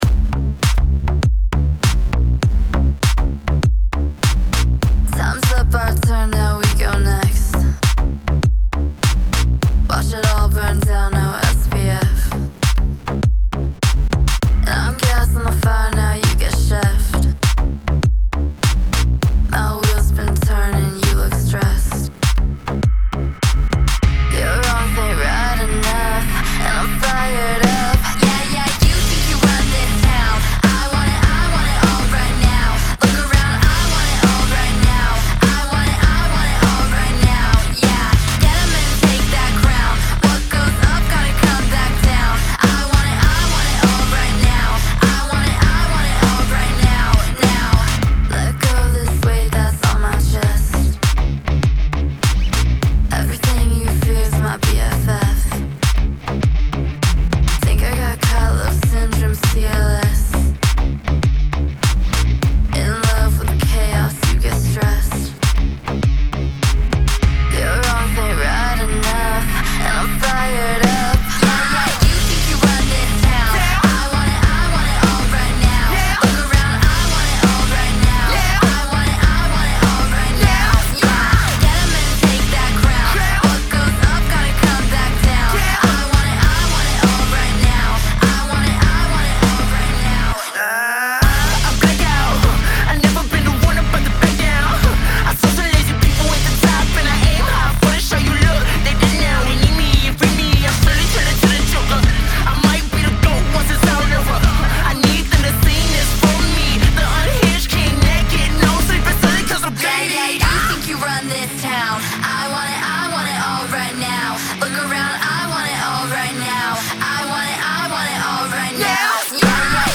BPM100-100
Audio QualityPerfect (High Quality)
Alt Pop / Rap song for StepMania, ITGmania, Project Outfox
Full Length Song (not arcade length cut)